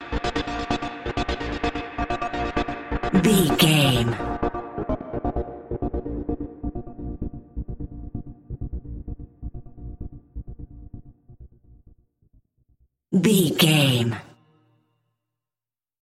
Epic / Action
Fast paced
Aeolian/Minor
B♭
Fast
aggressive
dark
driving
energetic
intense
drum machine
synthesiser
breakbeat
synth leads
synth bass